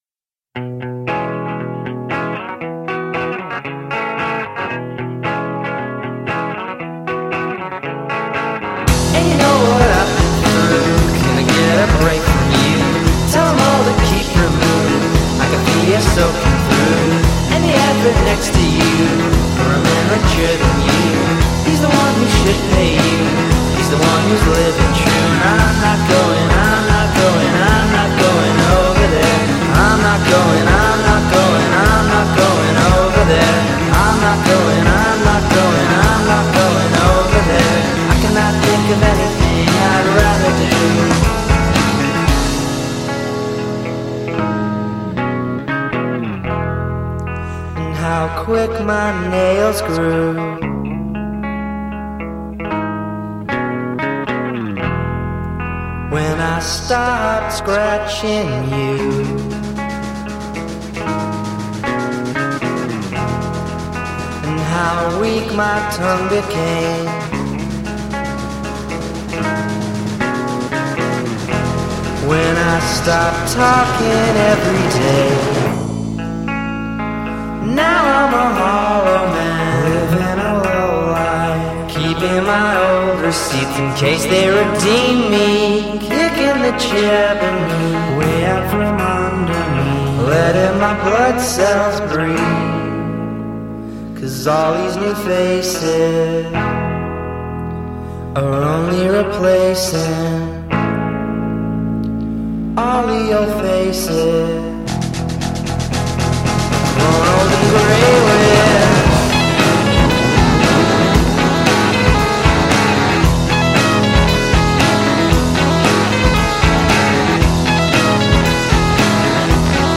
Sputnik Sound Studio di Nashville